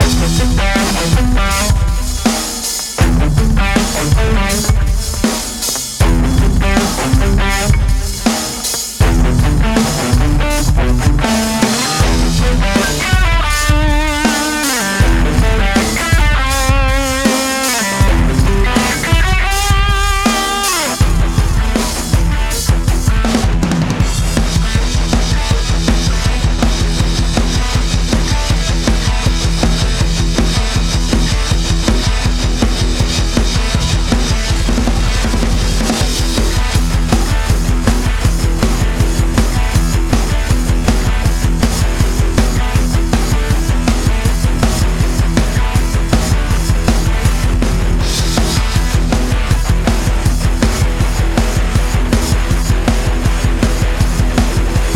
������� ��� ������: PRS Custom 24->pedal overdrive (��� ������ ��� ���������)->H&K Triamp 2 ����� ���-����->Di-�����->���������...